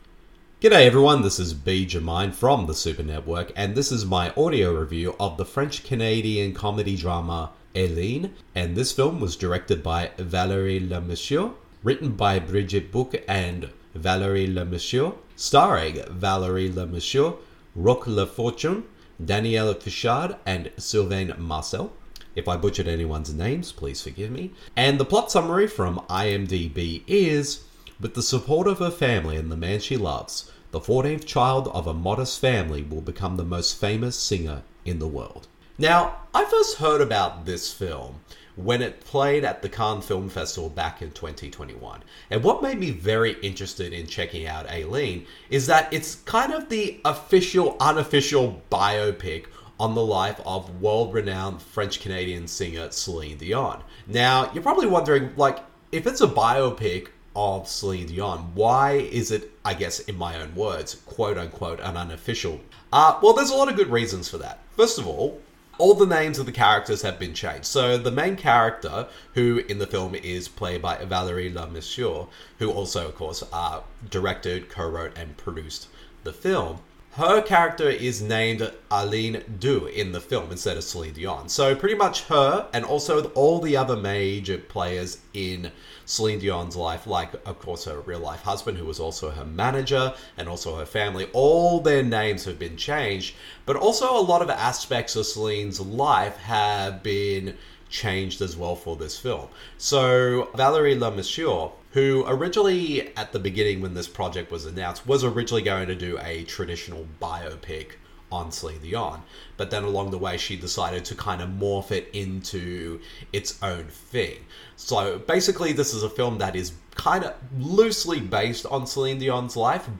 [Audio Review] Aline